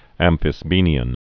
(ămfĭs-bēnē-ən)